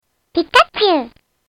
Звуки пикачу
Пикачу говорит голосом